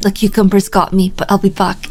Worms speechbanks
Kamikaze.wav